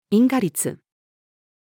因果律-female.mp3